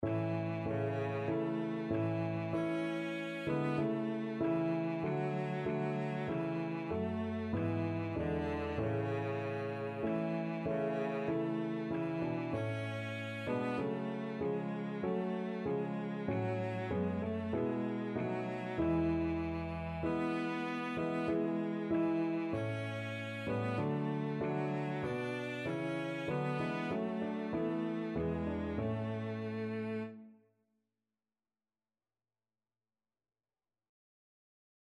Christmas
4/4 (View more 4/4 Music)